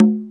Percussion #20.wav